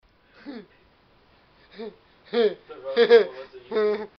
Laugh 36